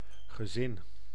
Ääntäminen
IPA: /ɣə.ˈzɪn/